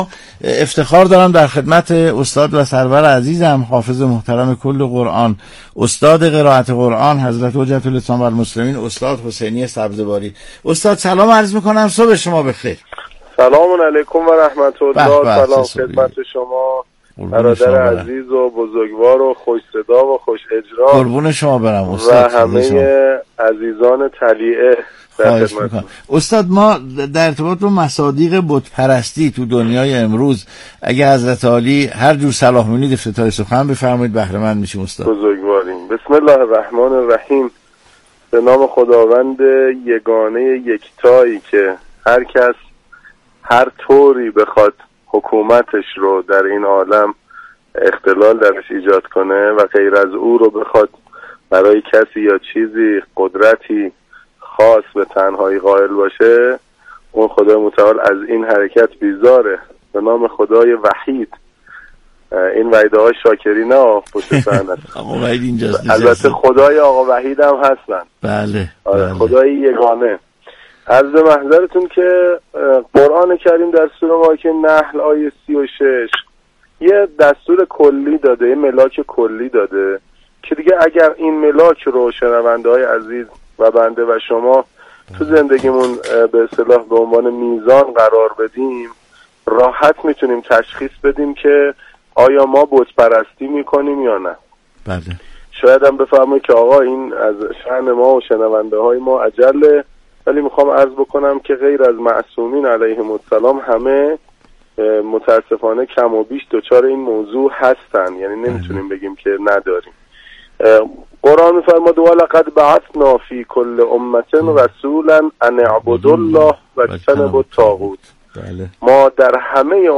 مجله صبحگاهی «طلیعه» با تحلیل و بررسی مصادیق بت‌پرستی در دنیای امروز، به روی آنتن شبكه رادیویی قرآن رفت.